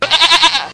Kambing_Suara.ogg